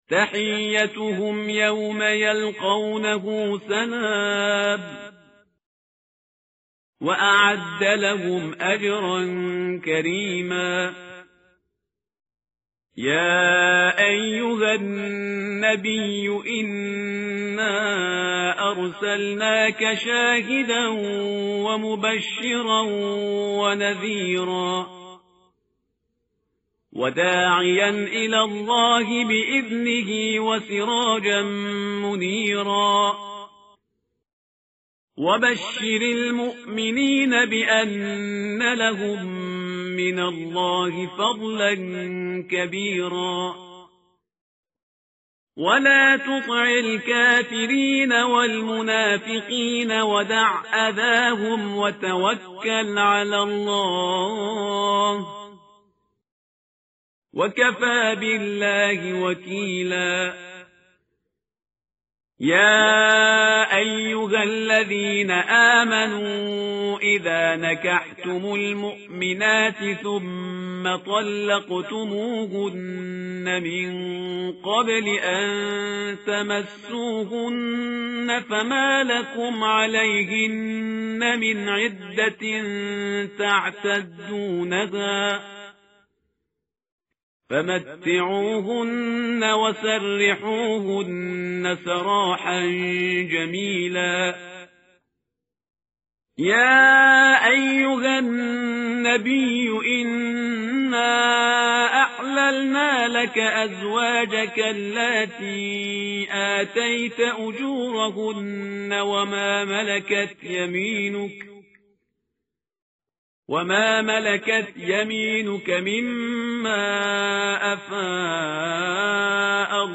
متن قرآن همراه باتلاوت قرآن و ترجمه
tartil_parhizgar_page_424.mp3